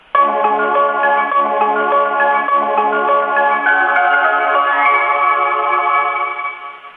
Using from effect sound collection.
Departure merody